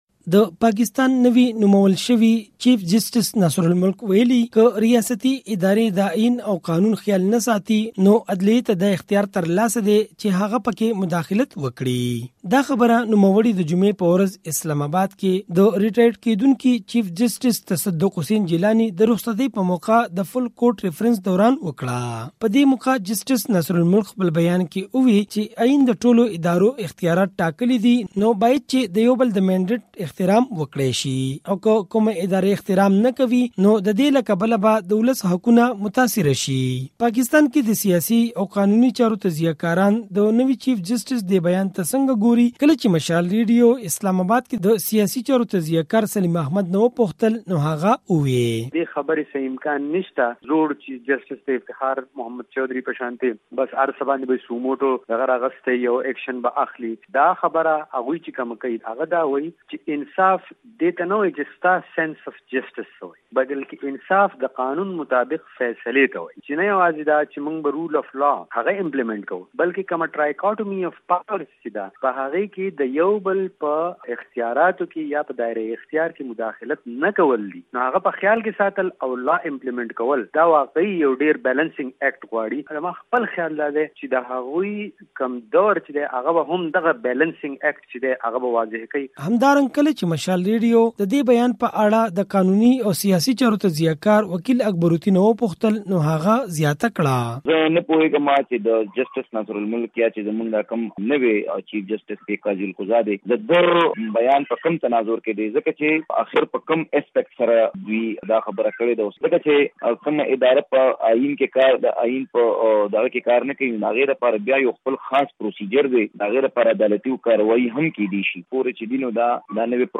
په دې اړه نور تفصیل له اسلام اباده د مشال راډیو په دې رپوټ کې واورئ